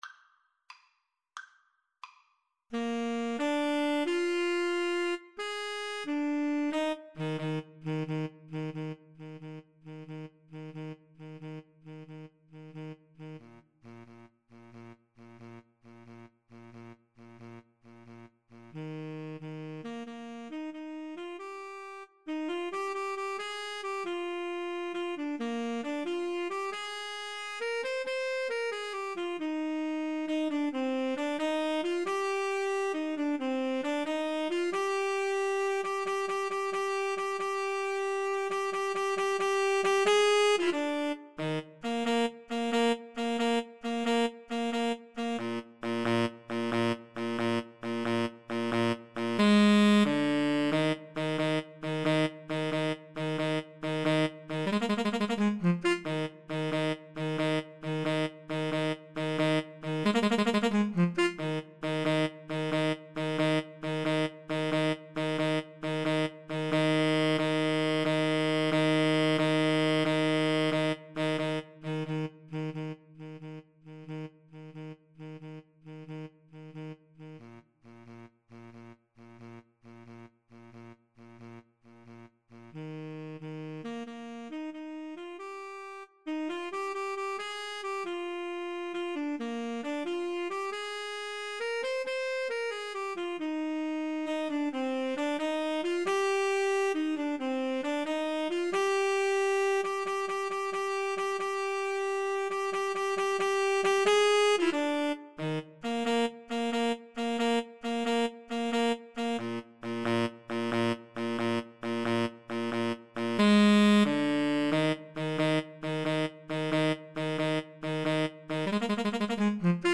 Tenor Sax 1Tenor Sax 2
Eb major (Sounding Pitch) (View more Eb major Music for Tenor Sax Duet )
6/8 (View more 6/8 Music)
Rollicking . = c. 90